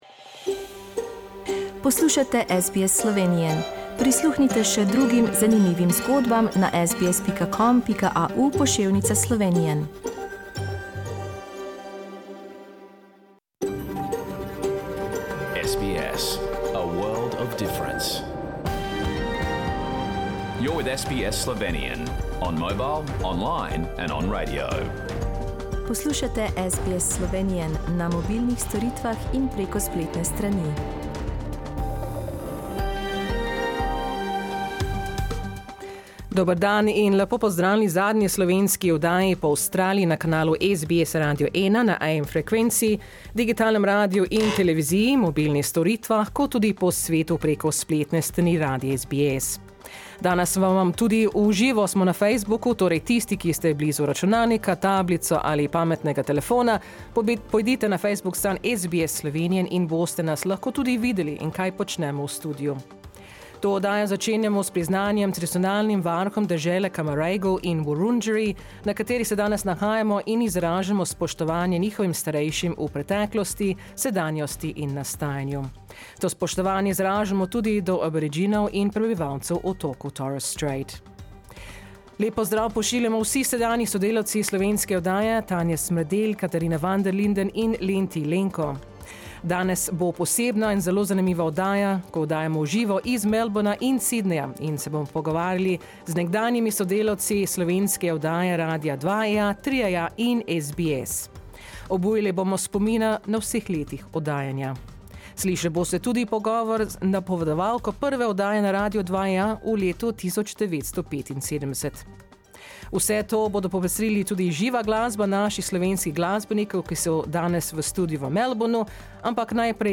Danes 29.aprila, 2023, je bila zadnja slovenska oddaja na SBS. Z nami so bili posebni gostje v studiu v Melbournu in Sydneyu in smo obujali spomine na 47 let oddajanja. Avstralskih 5 so tudi popestrili oddajo z živo slovensko glasbo.